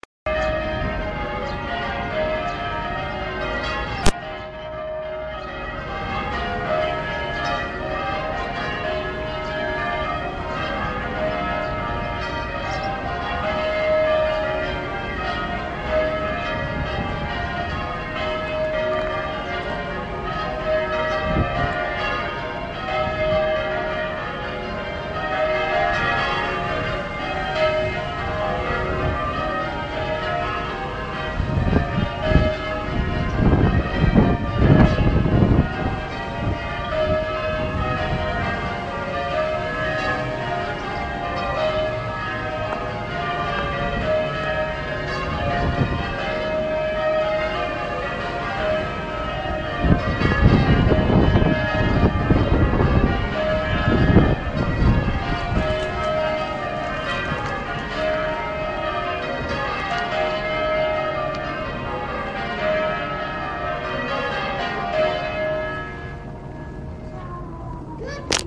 All Saints Bells